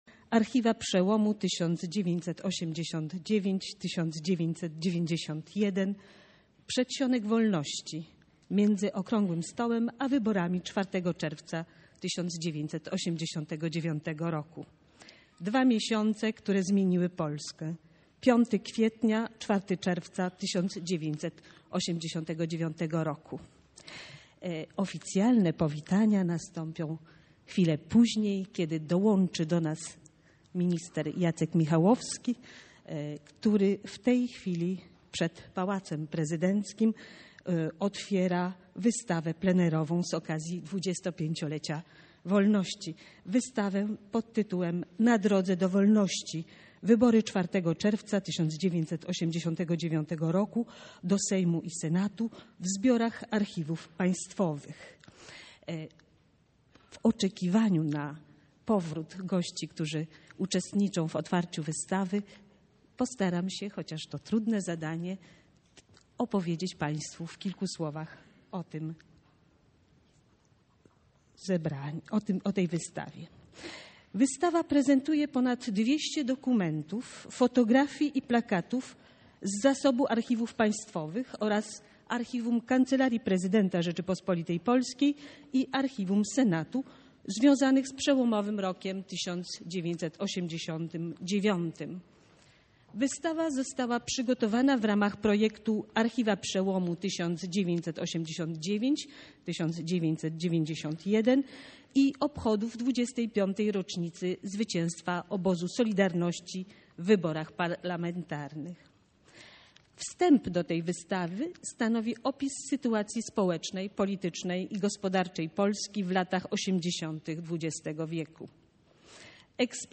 IV Seminarium w ramach projektu „Archiwa Przełomu 1989-1991”